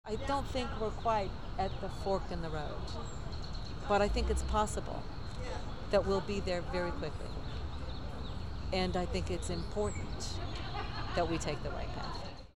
Digital Portfolio Sensibility: An Interview